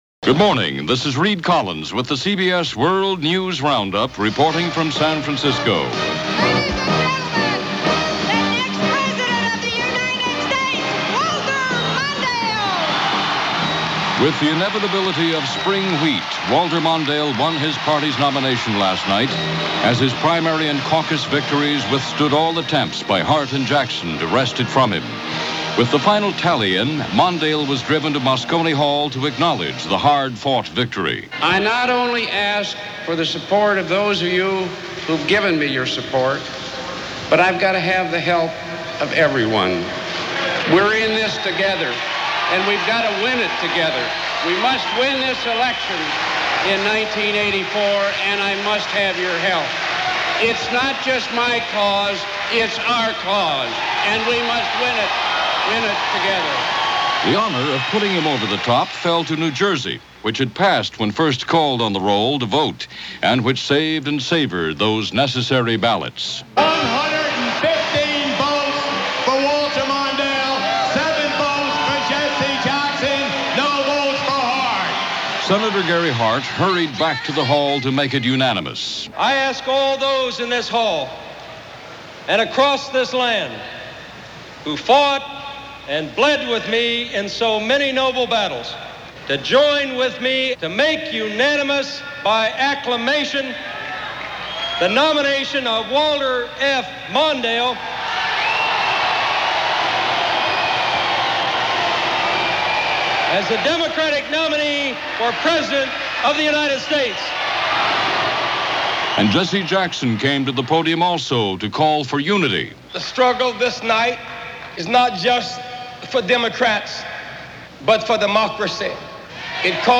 Mondale Clinches Nomination - Mass Murder At McDonalds - France Goes House-Cleaning - July 19, 1984 - CBS World News Roundup.